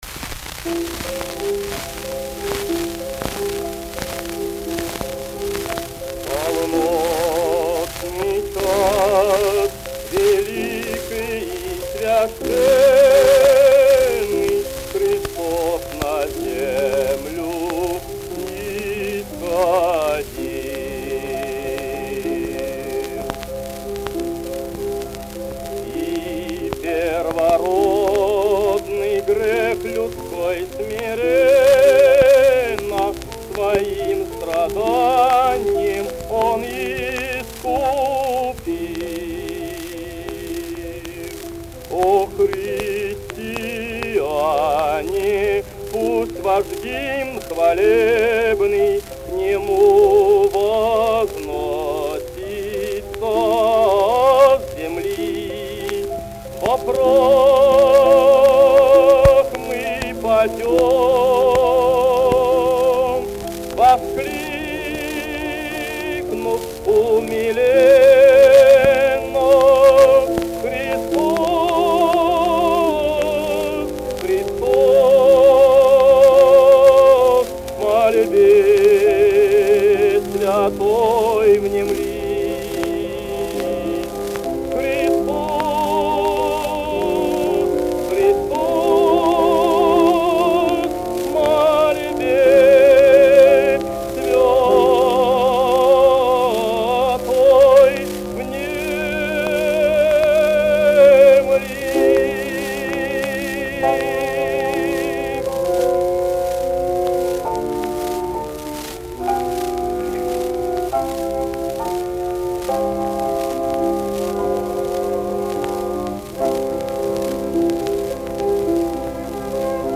Recorded April 3, 1913 in St. Petersburg